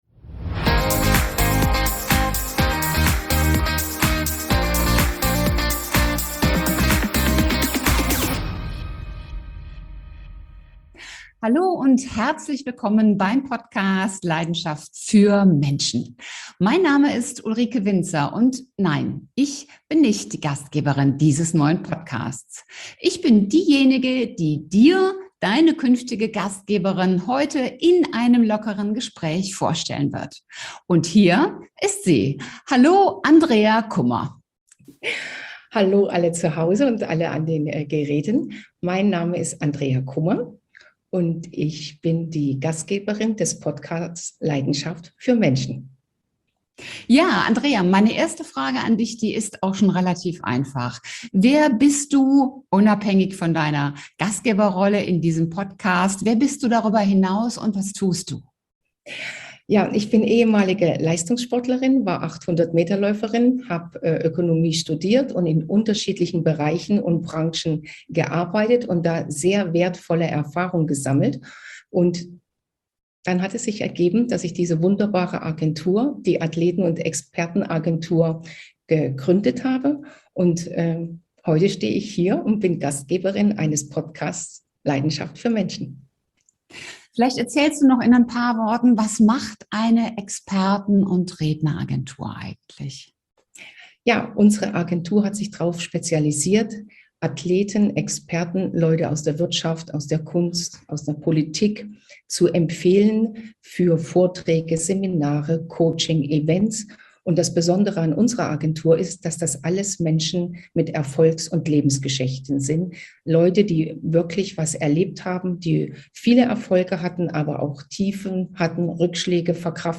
im Interview!